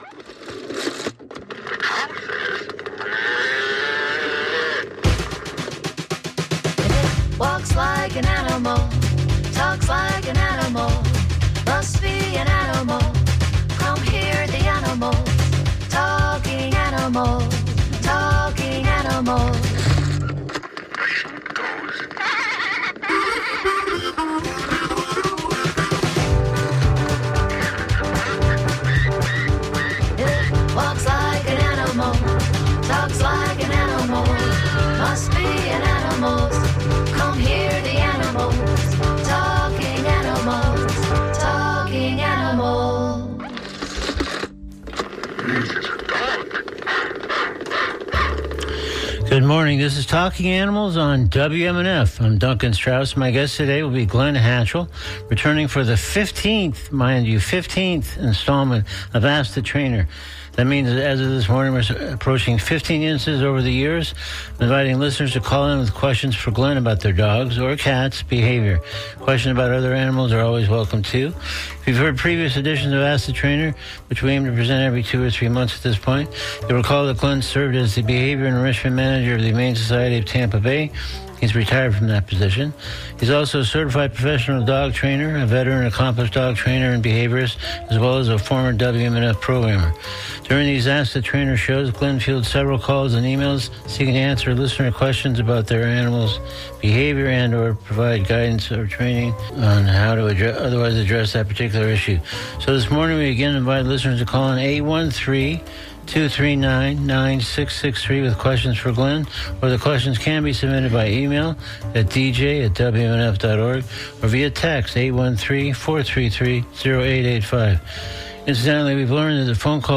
In each session of ATT, listeners are invited to call or email (or text) questions about their dogs or cats.